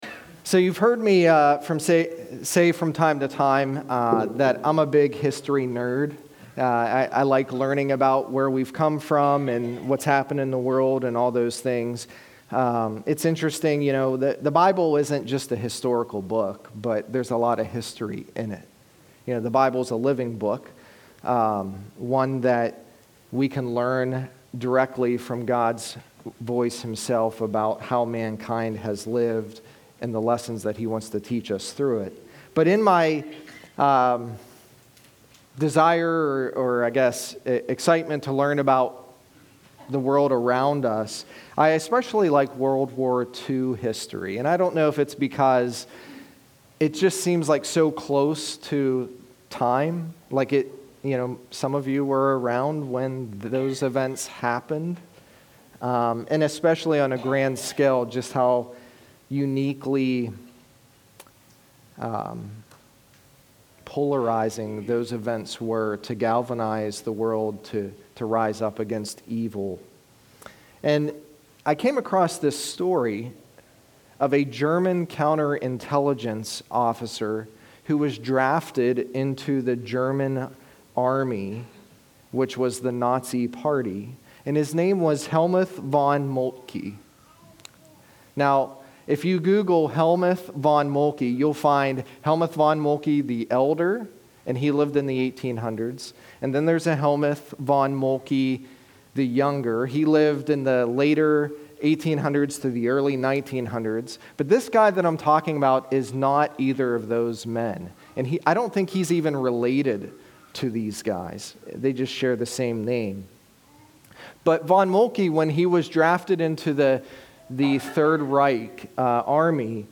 Sermons | North Annville Bible Church